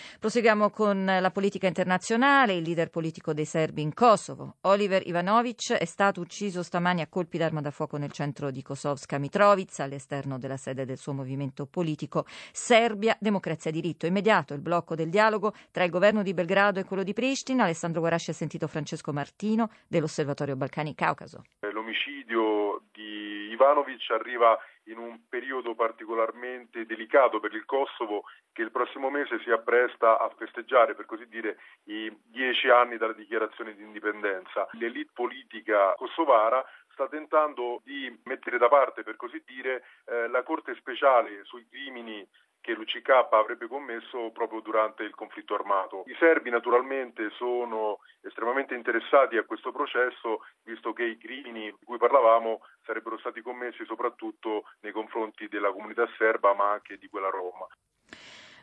intervenuto al GR di Radio Vaticana sull'omicidio del leader dei serbi in Kosovo, Oliver Ivanović, avvenuto stamattina a Mitrovica nord